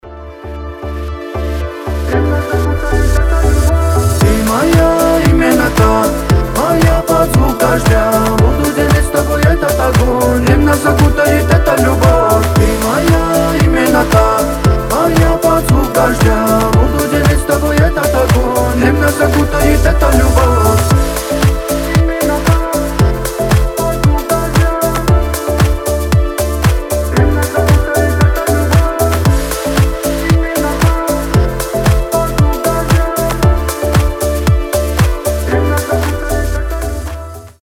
• Качество: 320, Stereo
поп
ритмичные
восточные мотивы